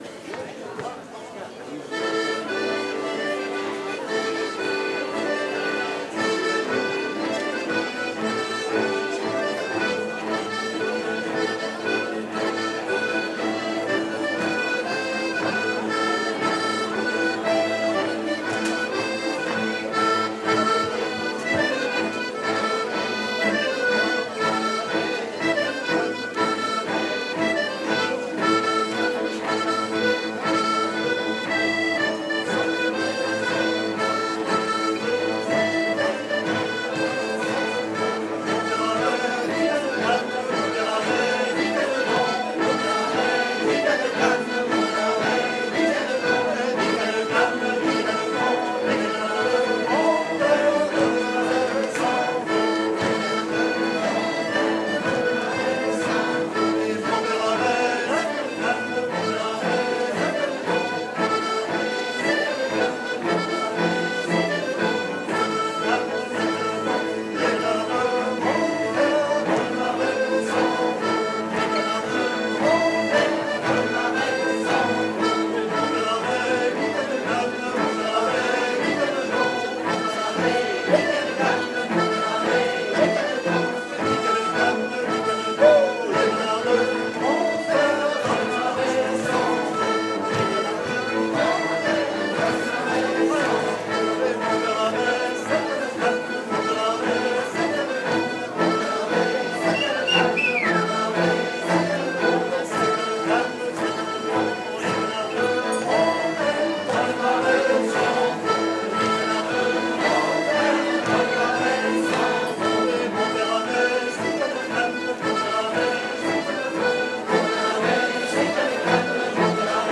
15_loudia_riqueniee-divers_instruments.mp3